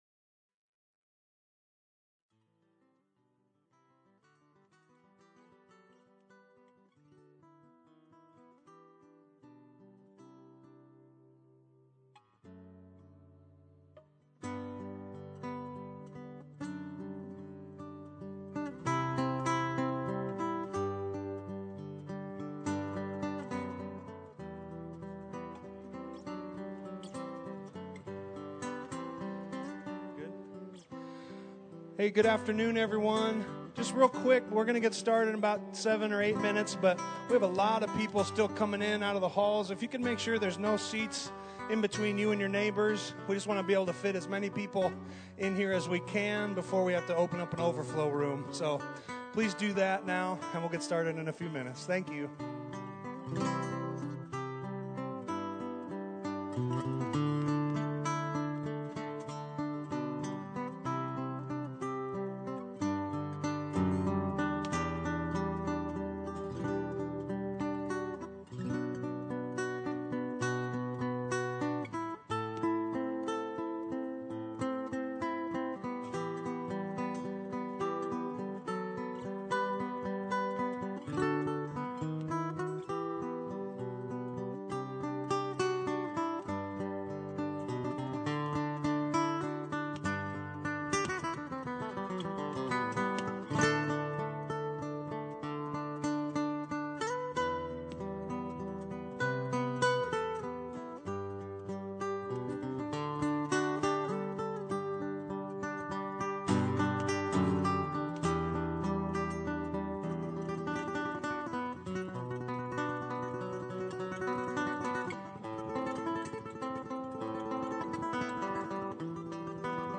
Sermon: Christmas Eve 2014
Sermon - Lincoln Berean